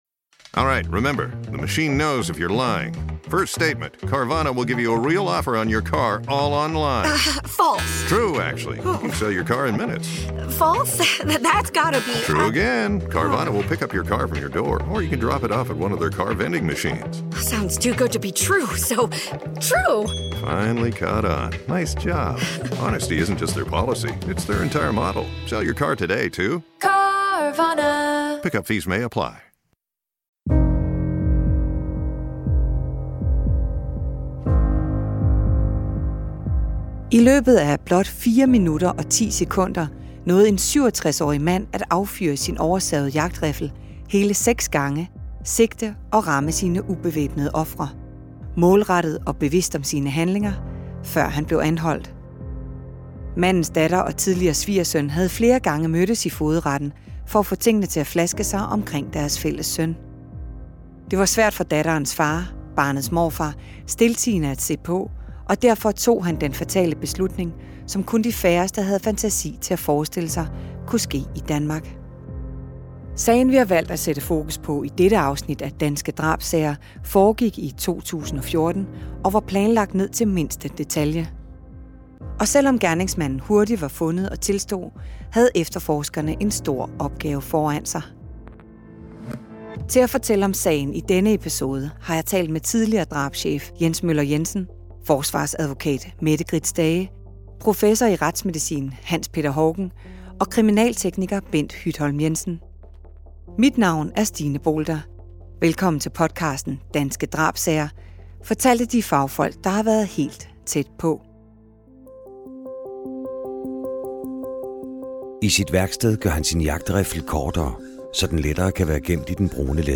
Hør drabschefens egen fortælling om de hektiske timer og dage, kom med kriminalteknikker og retsmediciner på arbejde og mød drabsmandens forsvarsadvokat, som fortæller om hendes kamp for en retfærdig straf.